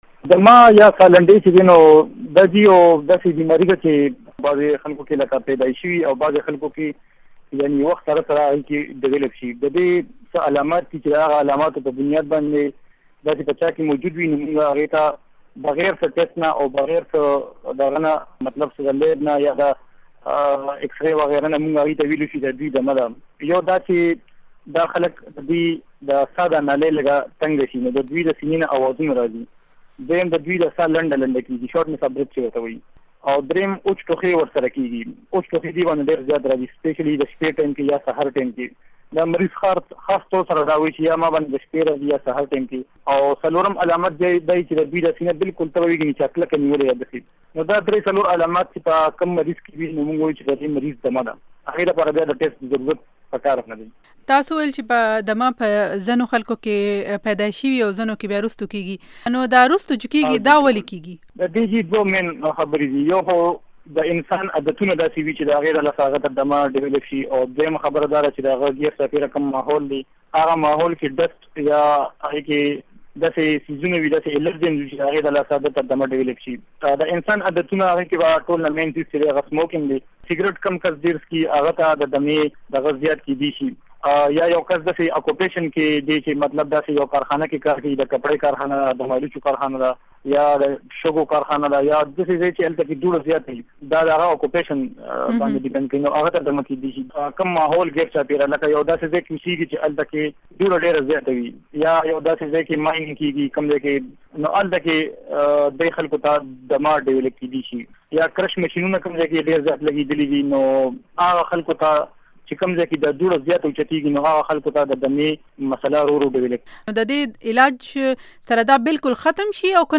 Intv on asthma